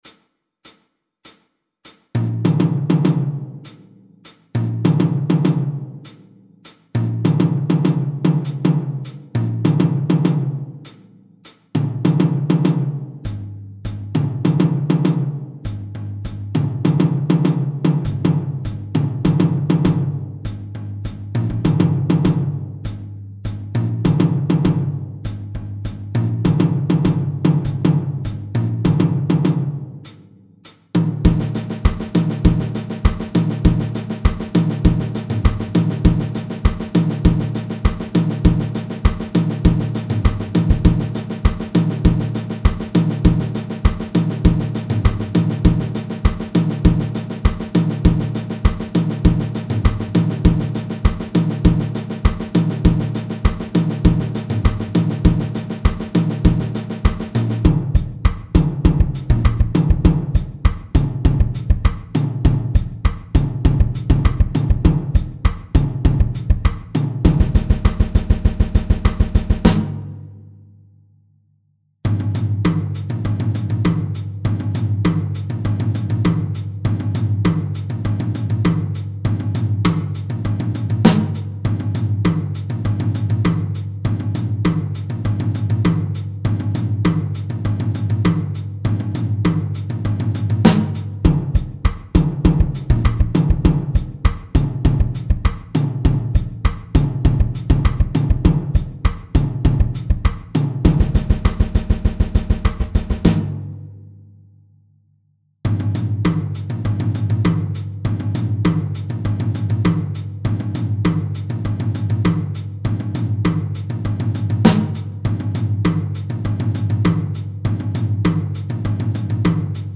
Ансамбль барабанщиков объединения
Тарелки
Большой барабан
batukada-2-chast.mp3